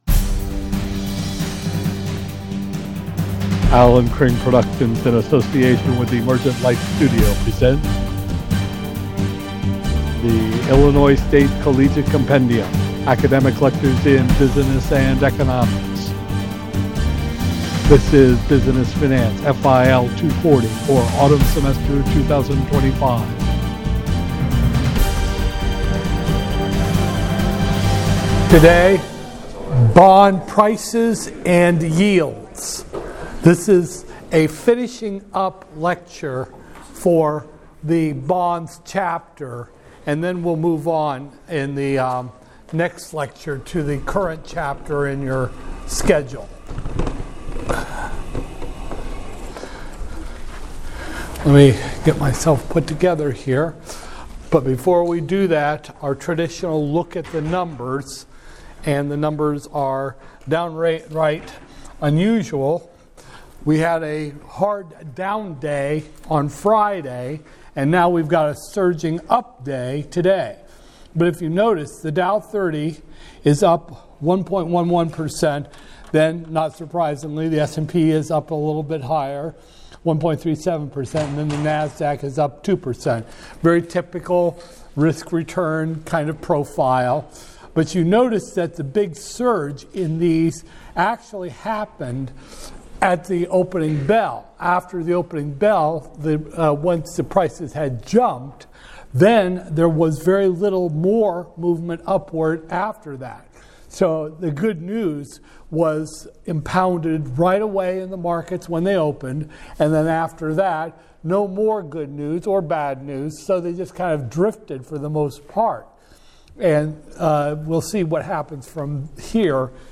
Business Finance, FIL 240-001, Spring 2025, Lecture 15